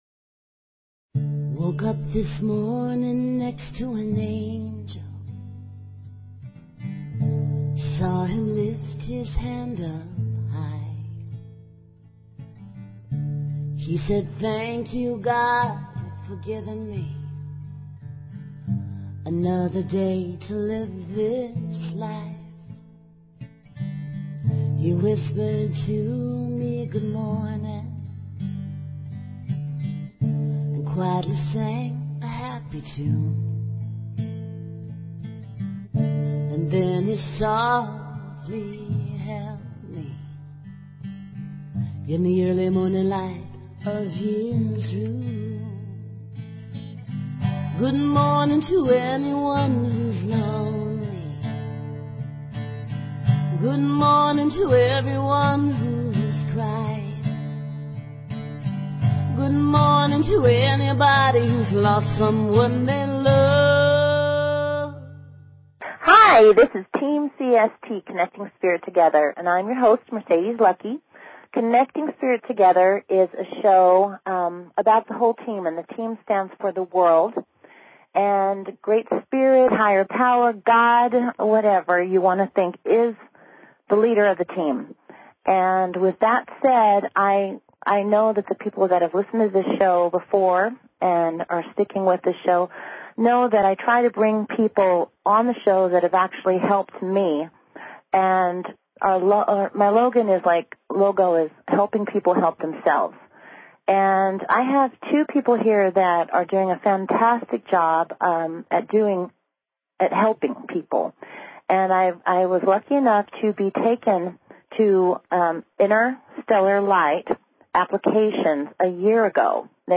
Talk Show Episode, Audio Podcast, Connecting_Spirit_Together and Courtesy of BBS Radio on , show guests , about , categorized as